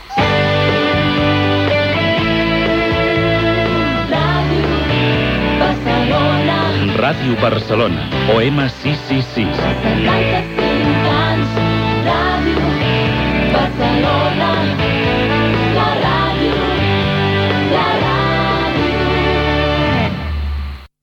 Indicatiu amb motiu del 75è aniversari de l'emissora i freqüència d'emissió a l'Ona Mitjana.